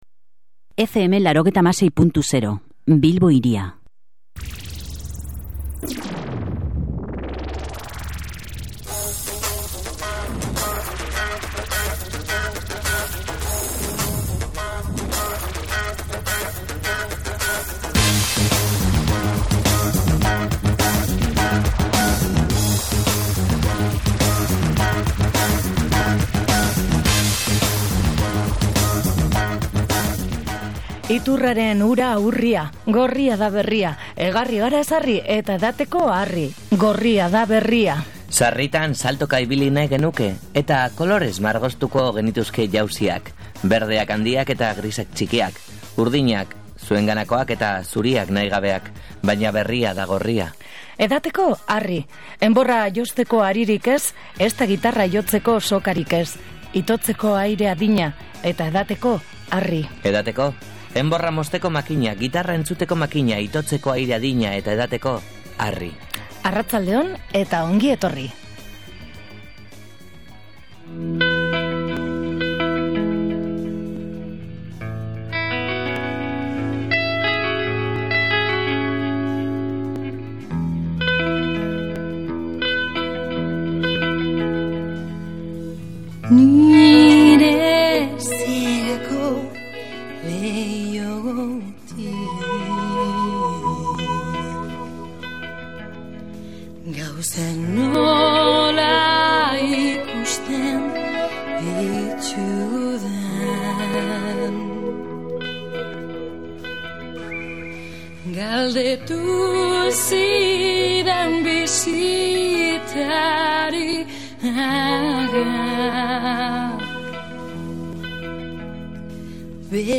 Gaurkoan jamaikako regge doinuak ekarri ditugu piperpolisgo uhinetara.